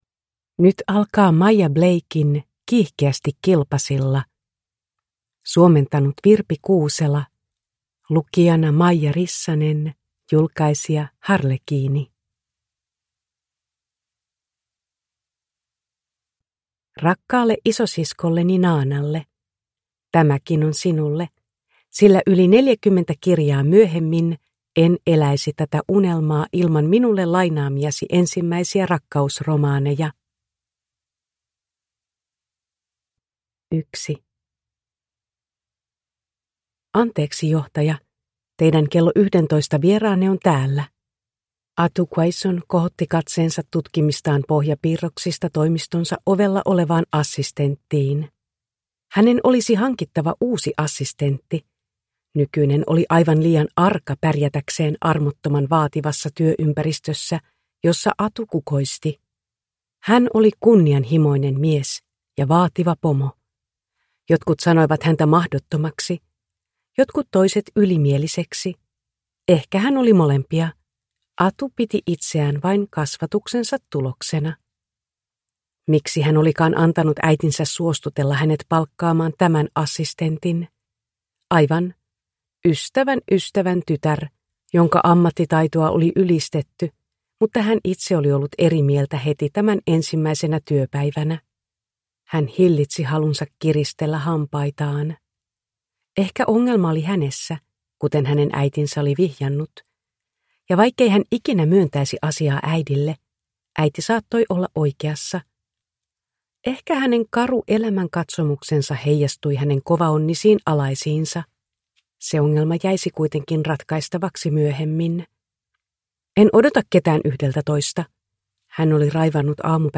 Kiihkeästi kilpasilla (ljudbok) av Maya Blake